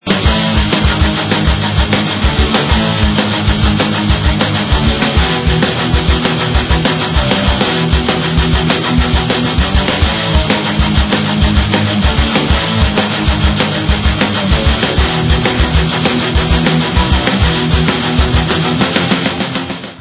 Brass punk from finland